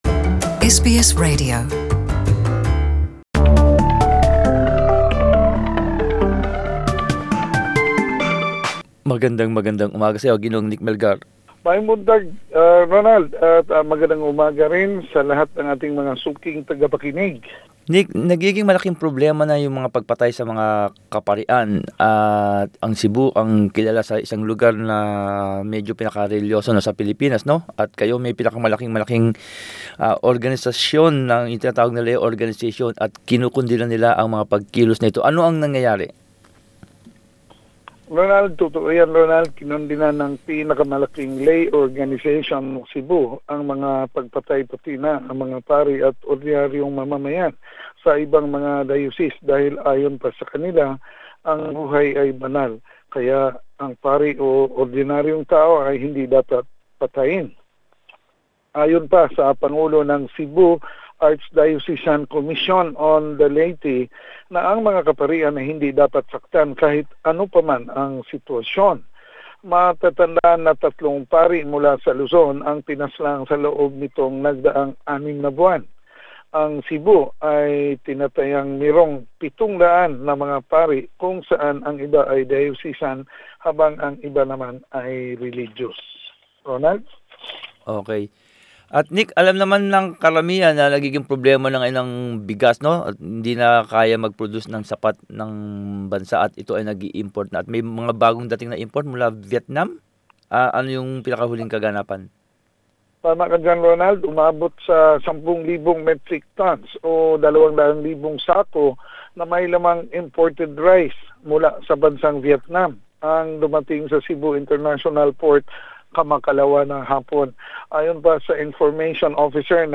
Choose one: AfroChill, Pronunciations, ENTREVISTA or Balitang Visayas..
Balitang Visayas.